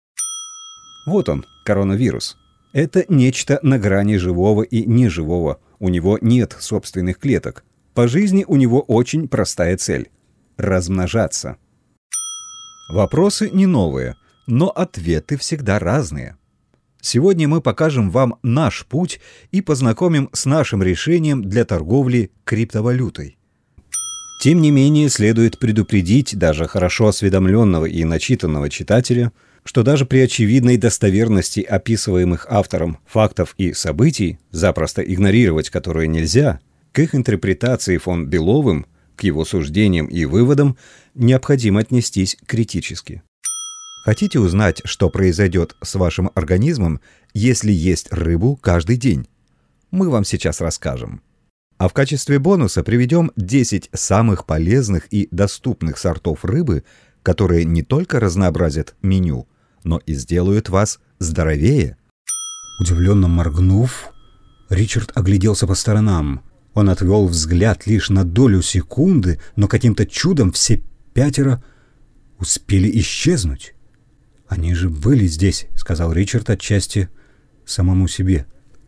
Услуги диктора. Демо нарезка.